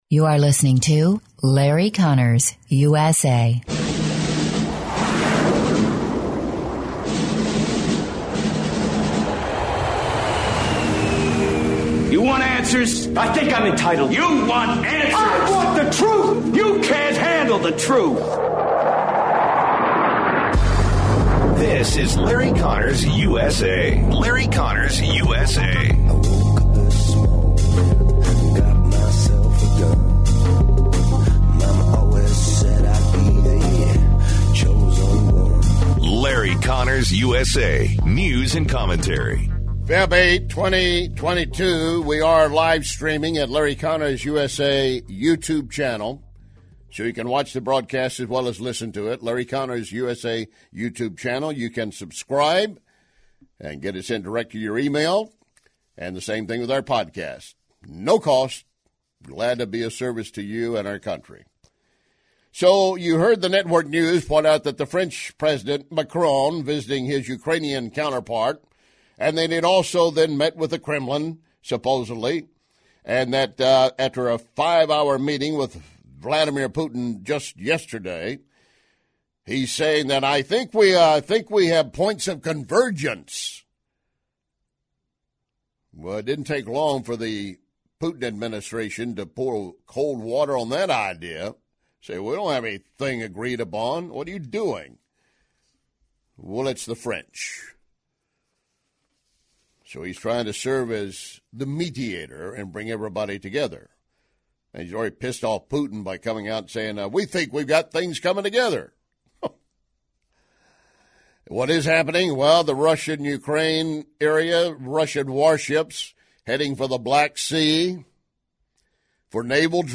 News and Commentary. Condolezza Rice says Russia my have bitten off more than it can chew. Trucker convoy is heading across America.